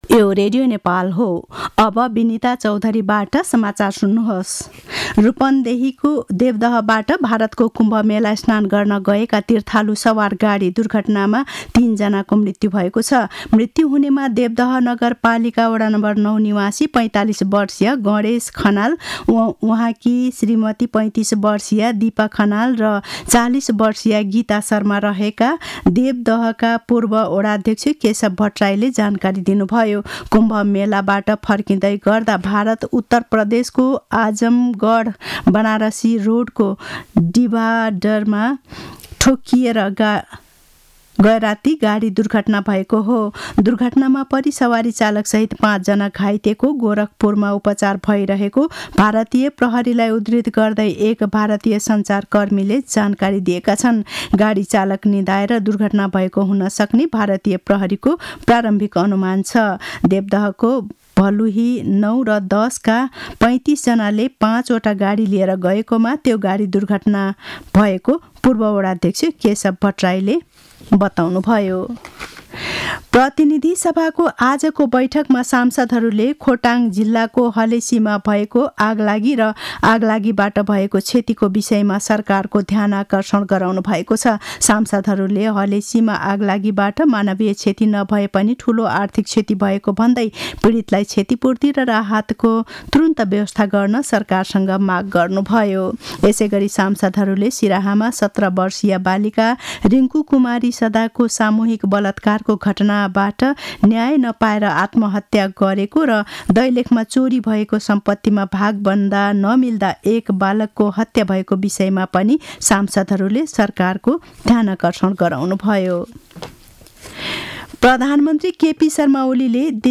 दिउँसो ४ बजेको नेपाली समाचार : ६ फागुन , २०८१
4-pm-news-4.mp3